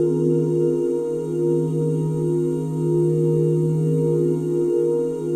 OOH D#MIN9.wav